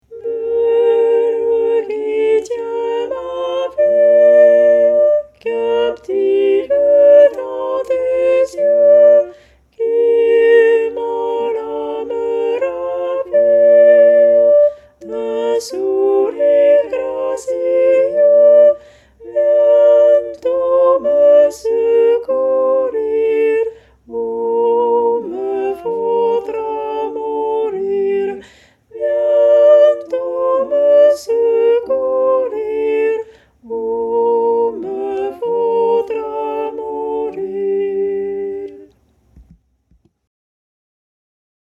VeRSION CHANTEE
Pavane-Tenors.mp3